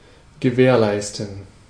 Ääntäminen
Vaihtoehtoiset kirjoitusmuodot (vanhentunut) guaranty Synonyymit assure warrant security commitment warranty assurity Ääntäminen US : IPA : [ˌgær.ən.ˈti] UK : IPA : /ˌɡæɹənˈtiː/ Tuntematon aksentti: IPA : /ˌɡær.ən.ˈti/